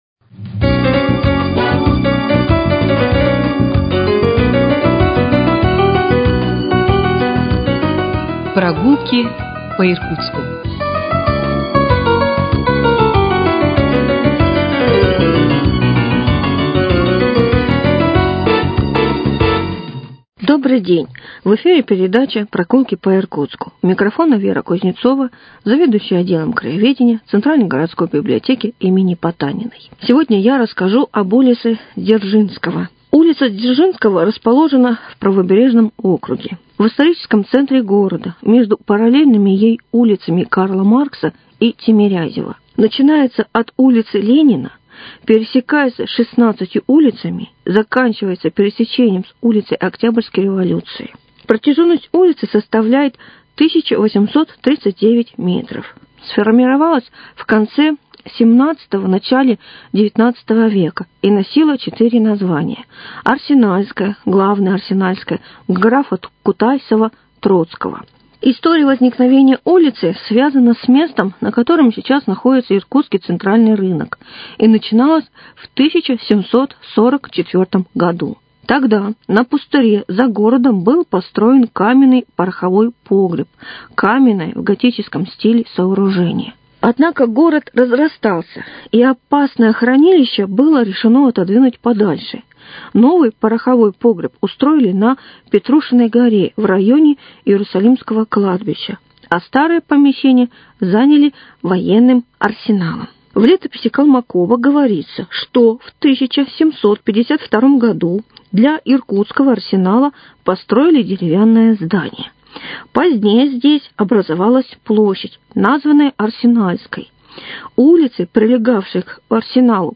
Мы продолжаем цикл передач - совместный проект радиоканала и Центральной городской библиотеки им. Потаниной.